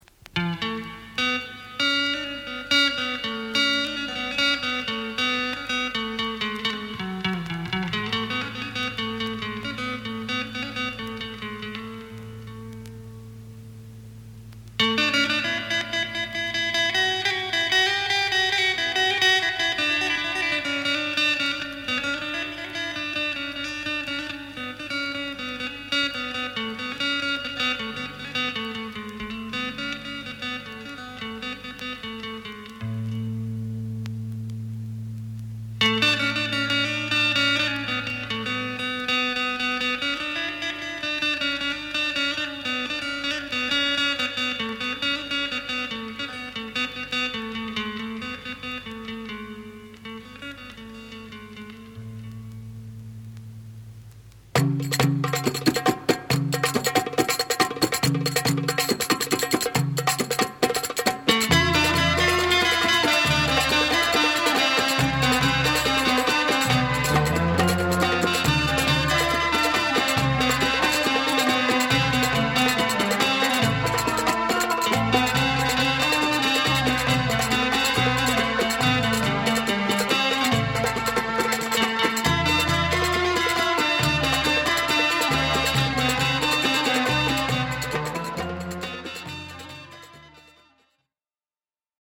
レバノン産レア・グルーヴ ベリーダンス 試聴 LP
ミドルイースタンな冒頭からクンビア・フィーリングな中盤、後半にかけ転調を繰り返す